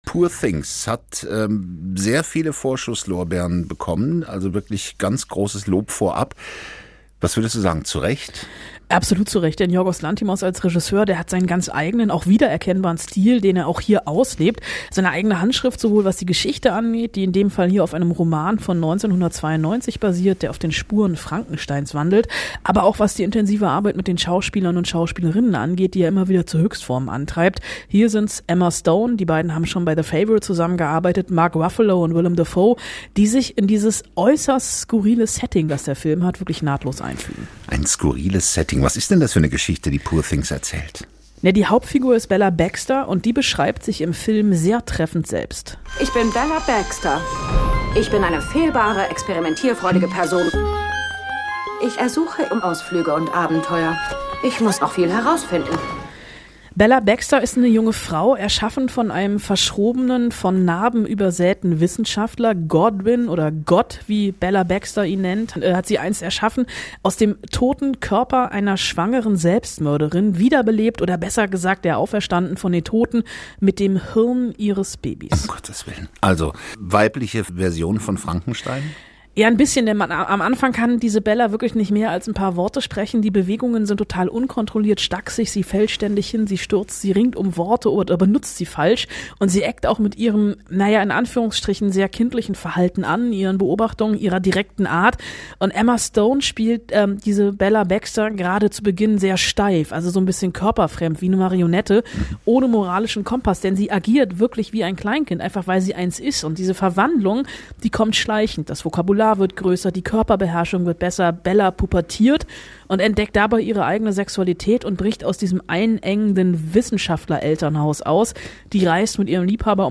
Filmkritikerin
im Gespräch